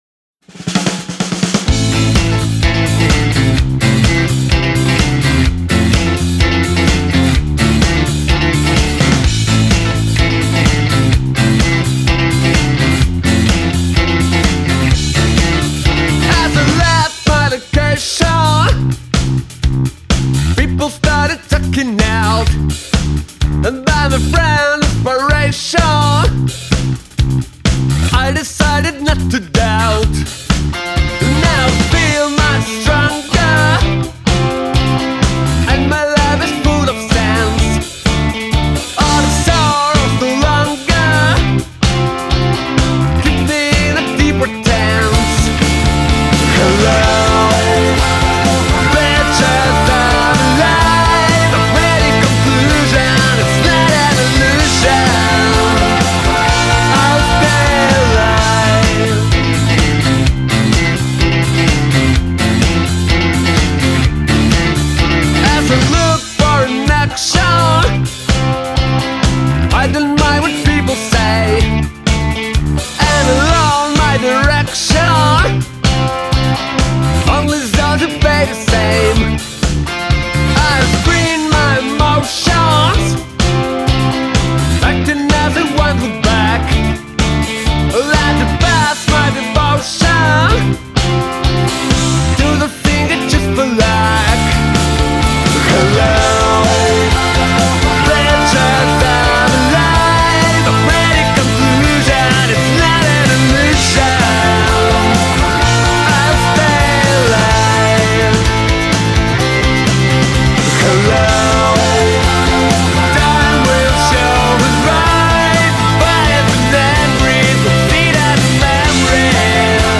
Группа в составе: ритм-гитара, бас и барабаны ищет соло-гитариста.